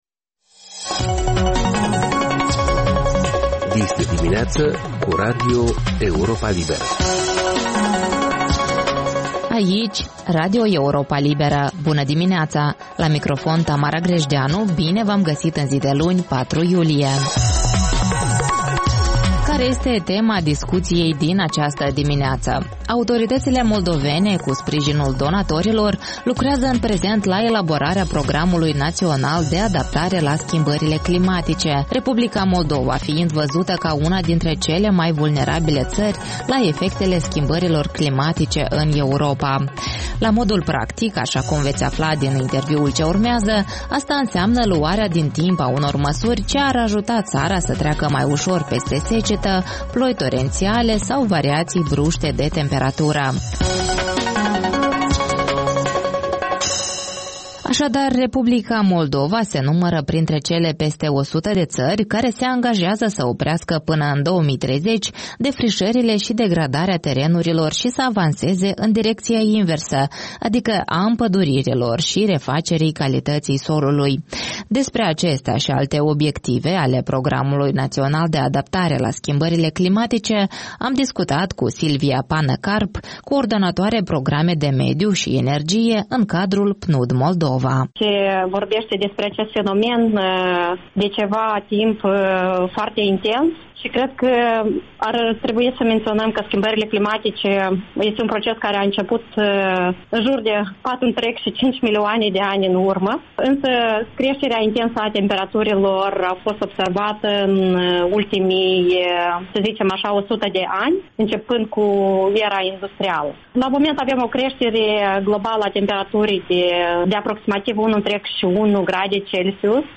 Ştiri, informaţii, interviuri, corespondenţe. De luni până vineri de la ora 6.30 la 6.45.